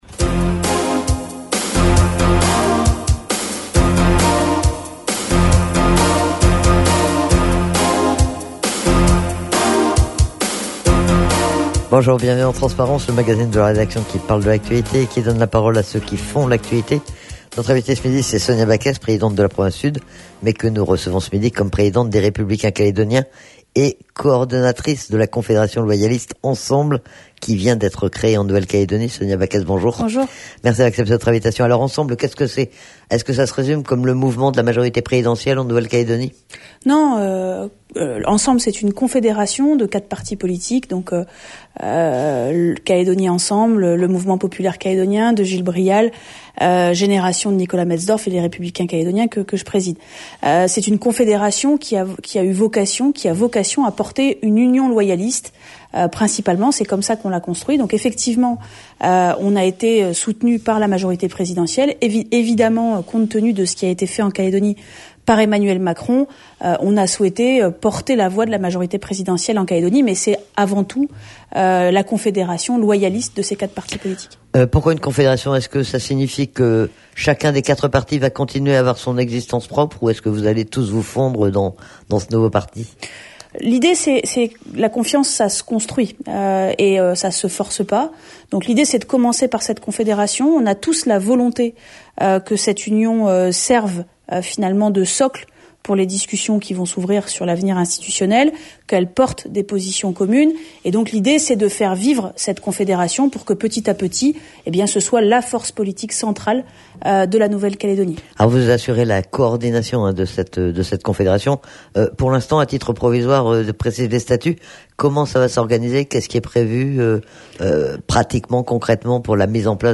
La présidente des Républicains calédoniens est interrogée sur la création de la confédération loyaliste "Ensemble !" dont les statuts viennent d'être déposés et dont elle assure la coordination. Elle commente également les résultats des législatives en Nouvelle-Calédonie et à l'échelon national.